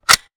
weapon_foley_pickup_12.wav